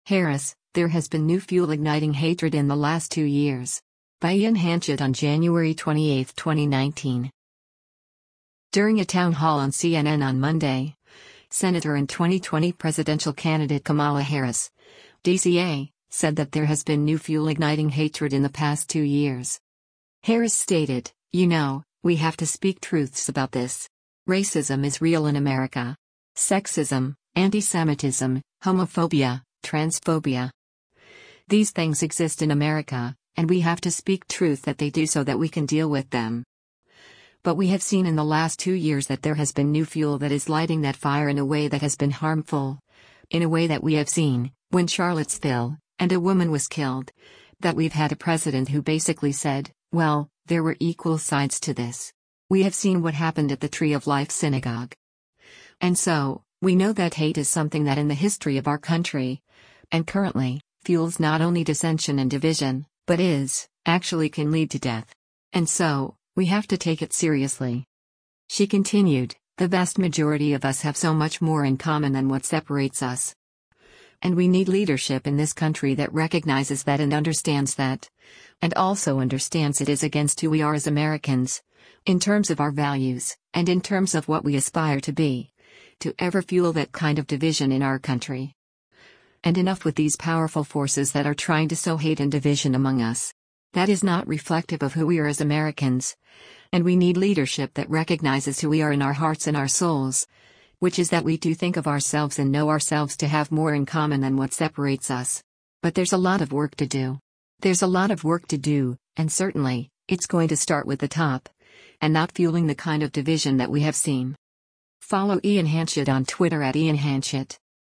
During a town hall on CNN on Monday, Senator and 2020 presidential candidate Kamala Harris (D-CA) said that there has “been new fuel” igniting hatred in the past two years.